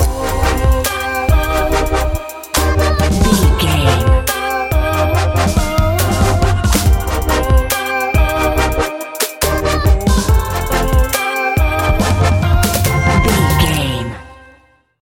Aeolian/Minor
Fast
dreamy
bouncy
energetic
electric guitar
synthesiser
drum machine